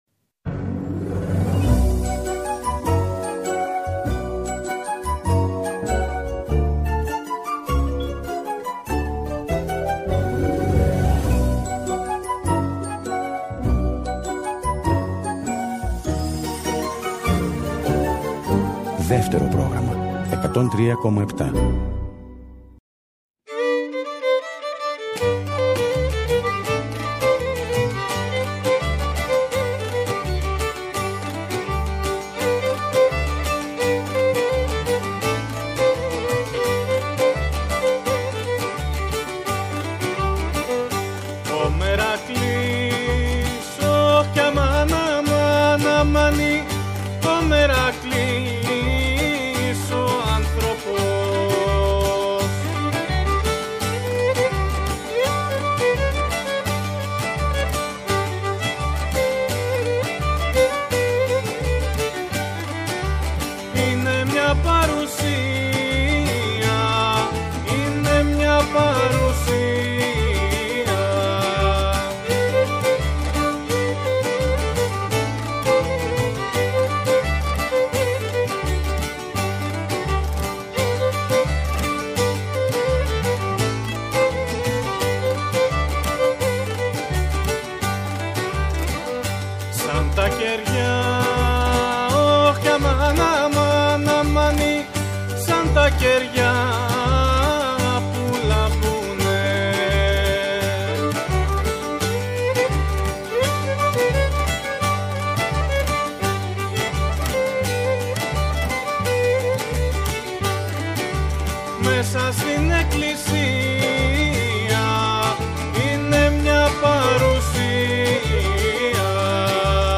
κοντραμπάσσο, φωνή
βιολί
λαούτο
Οι Φυστικί Σαλόνι στο studio E. Ένα πανόραμα της παραδοσιακής μουσικής της χώρας μας μέσα από ολόφρεσκες, ζωντανές ηχογραφήσεις με σύγχρονα συγκροτήματα, παρουσιάζει το Δεύτερο Πρόγραμμα από την Κυριακή, 5 Φεβρουαρίου και κάθε Κυριακή στις 2 το μεσημέρι.
Live στο Studio